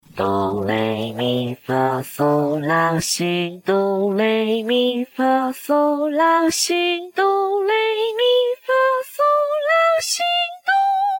幸CHNVCV_冷静（normal）                 DL
収録音階：C4